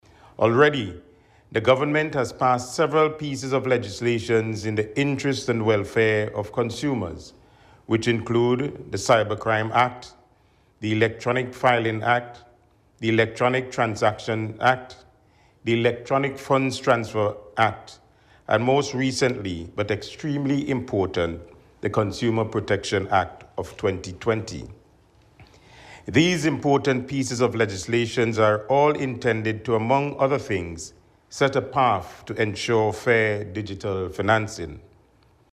This assurance came from Minister responsible for Consumer Affairs, Frederick Stevenson, as he delivered a message to mark World Consumer Rights Day.
In his address, Minister responsible for Consumer Affairs, Frederick Stephenson said the Government is mindful of the concerns of consumers here in SVG.